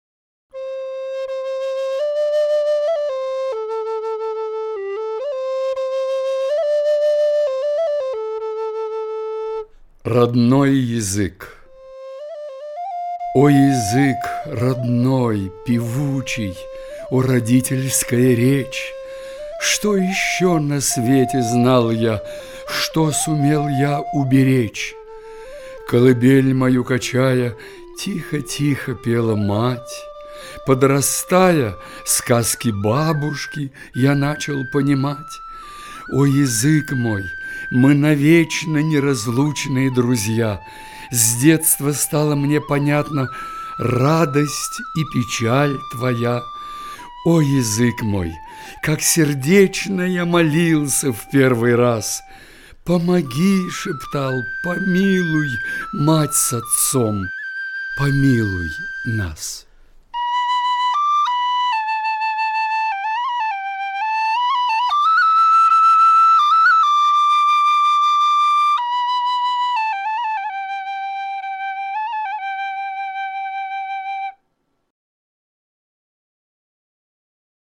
Габдулла_Тукай_-_Родной_язык_читает_В__Лановой
Gabdulla_Tukaq___Rodnoq_yazyk_chitaet_V__Lanovoq.mp3